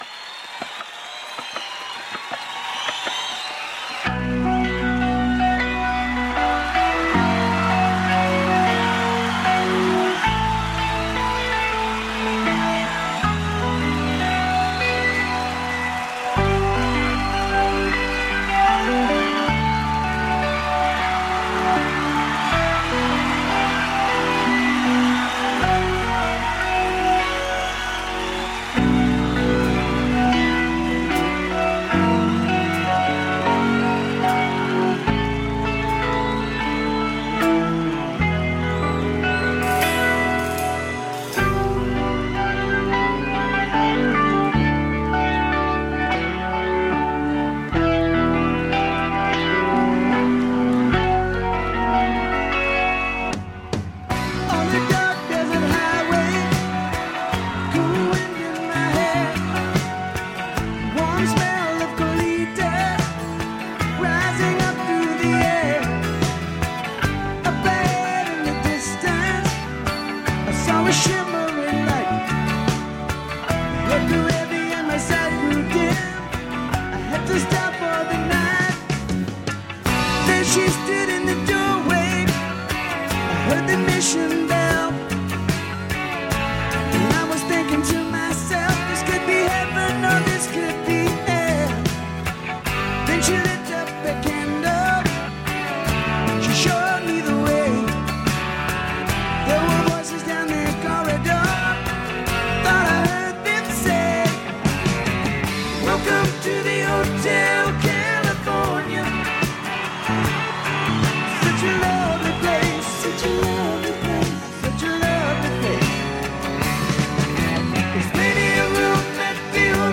“Arezzo svegliati” Fuochi e rifiuti 6° puntata, in studio l’assessore Marco Sacchetti – RadioFly